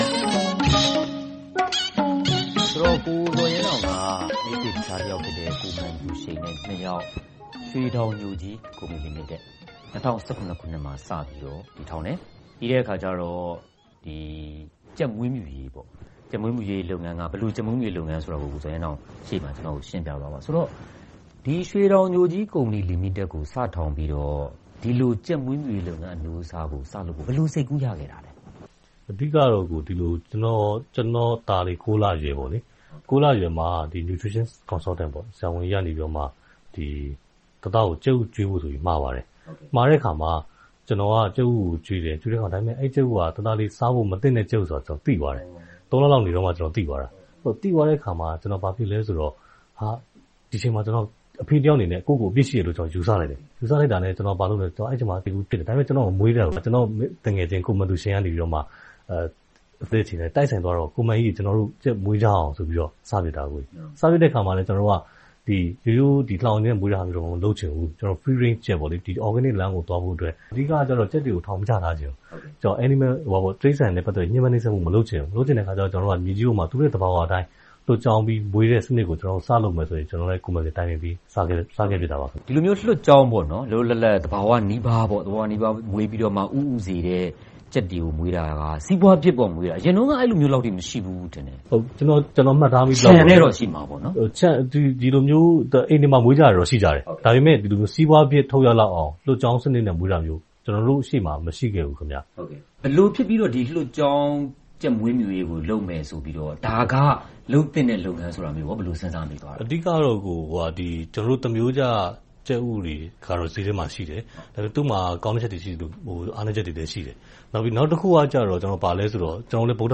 တွေ့ဆုံမေးမြန်းထားပါတယ်။